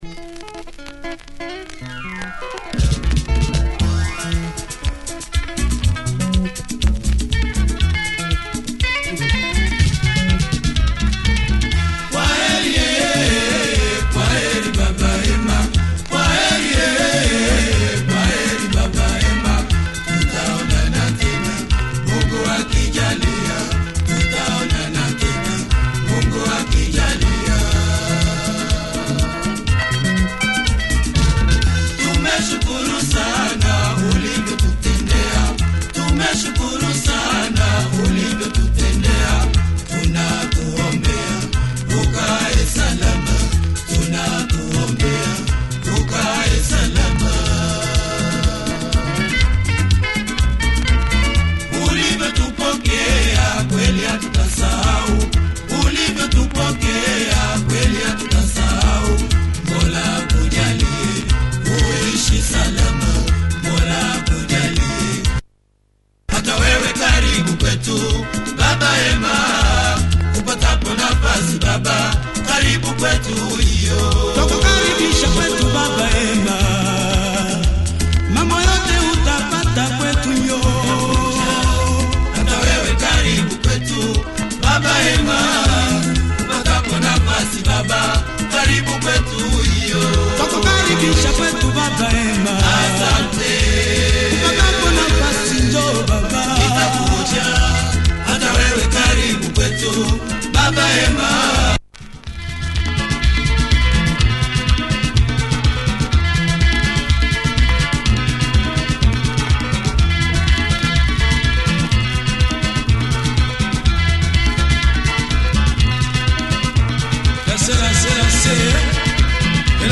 Good production quality in this label.